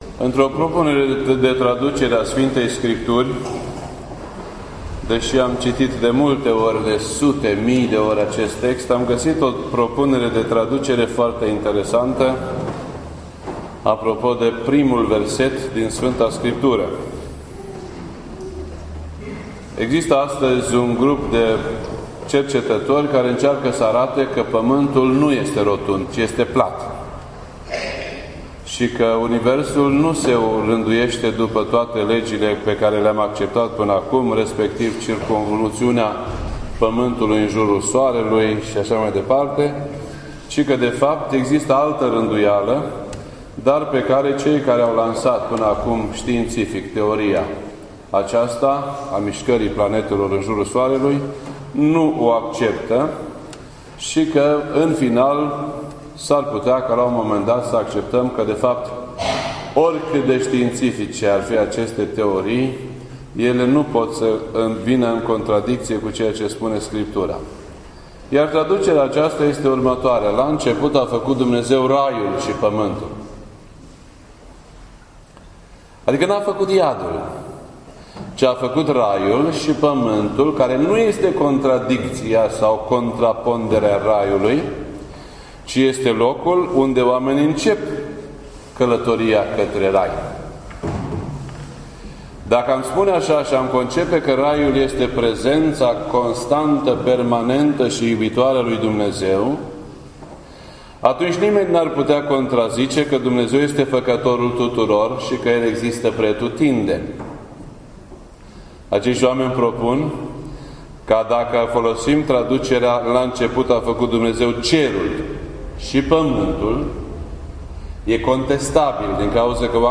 This entry was posted on Sunday, March 13th, 2016 at 3:59 PM and is filed under Predici ortodoxe in format audio.